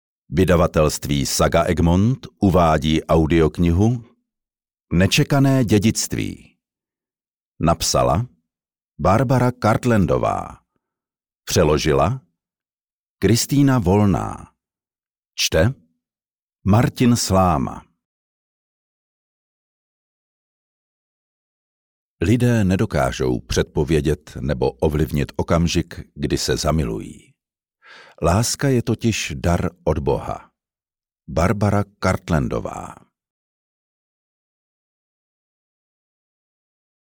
Nečekané dědictví audiokniha
Ukázka z knihy